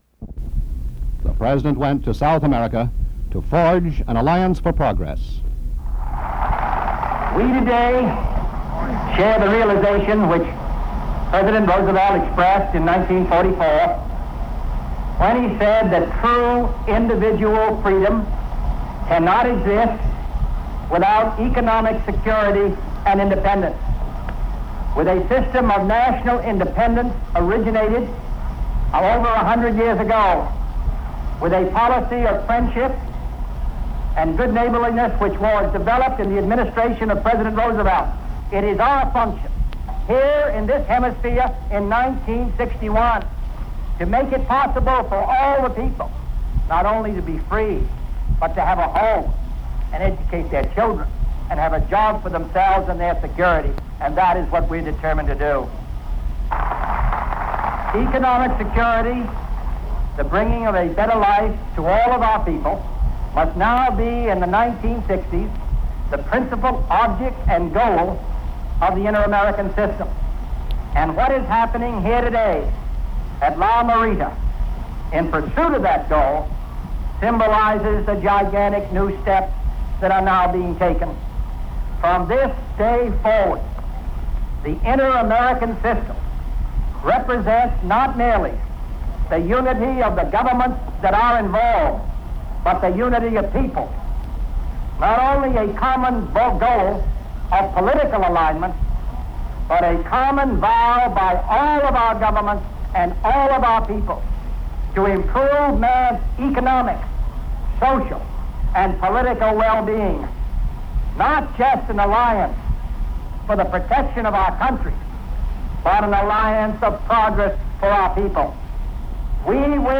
JFK address to the nation
John F. Kennedy speaks on the Alliance for Progress. Includes the voice of Chet Huntley.